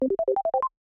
User Interface, Alert, Success, Access Granted, Dry, Soft Keys SND57532.wav